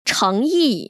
[chéngyì] 청이  ▶